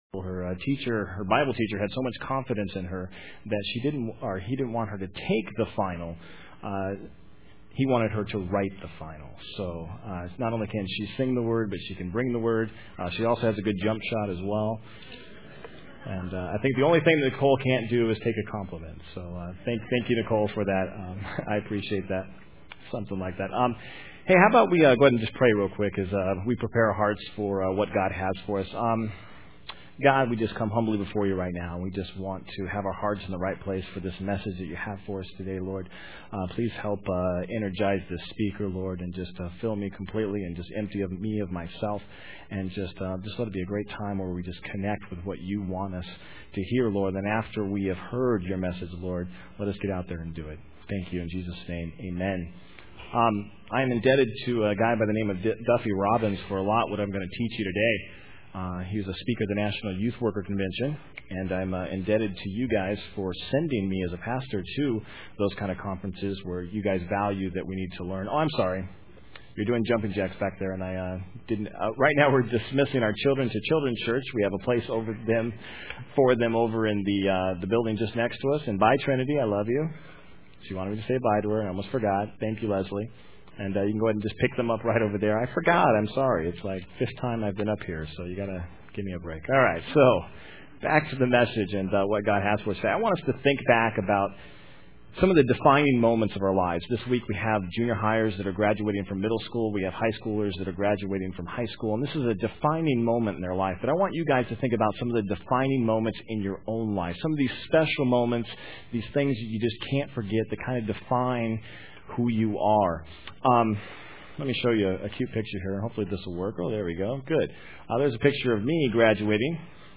Main Service am